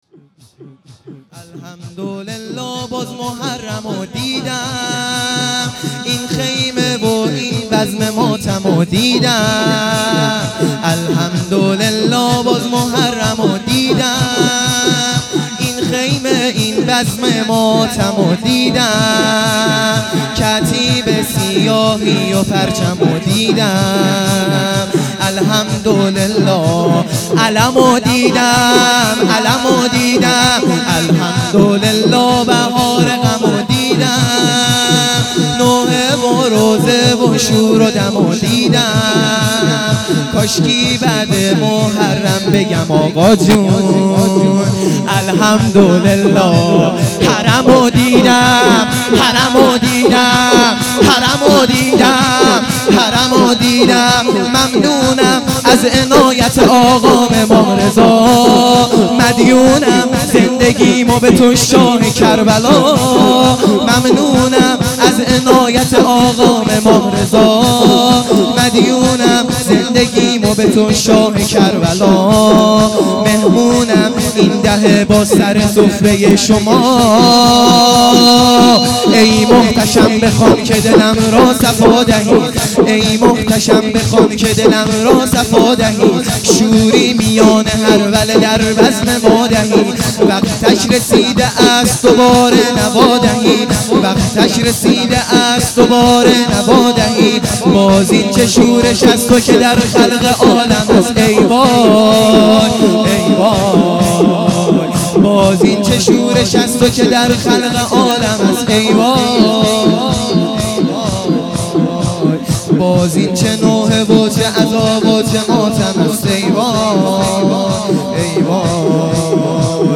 شور | الحمدالله باز محرم دیدم